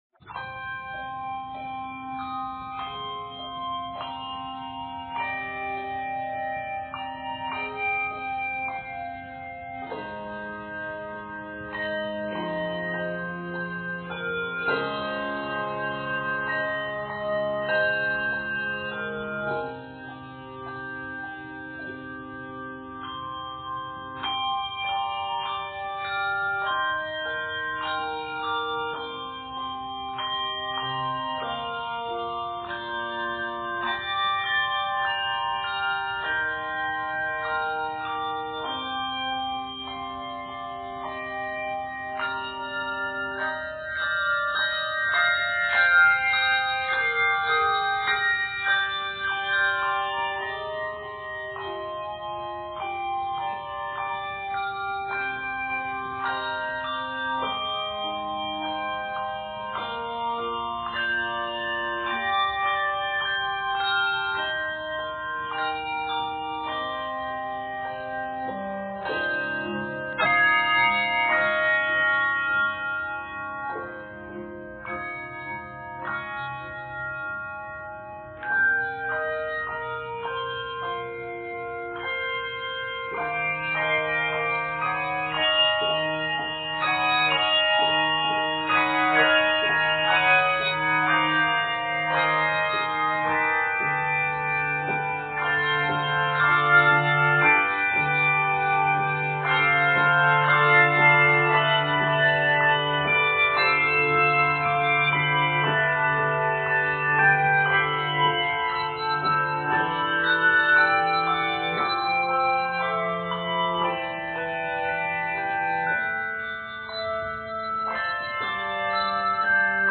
for 3 to 5 octaves of handbells